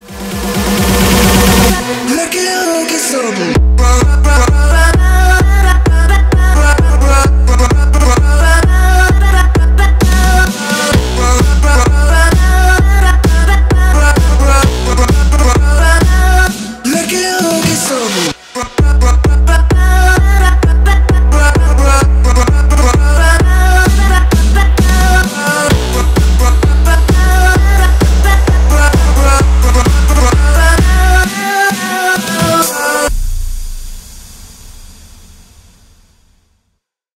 веселые
Electronic
EDM
качающие
Big Room
dutch house